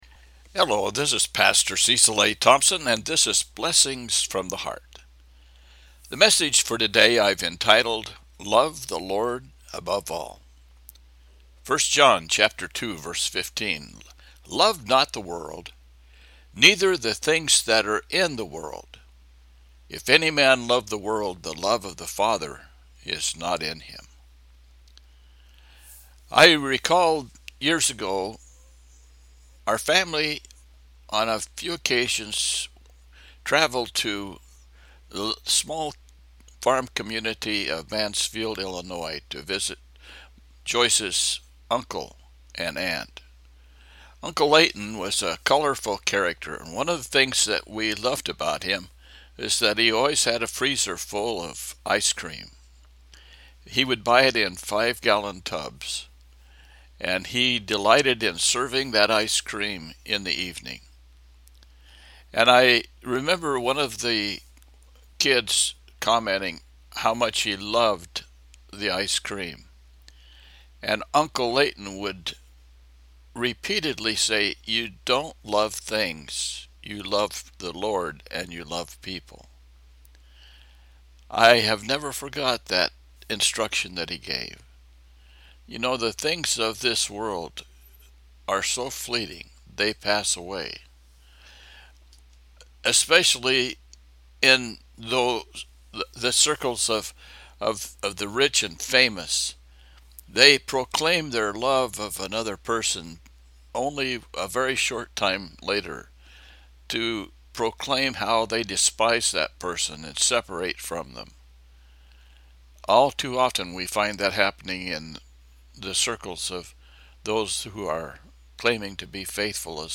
1 John 2:15 – Devotional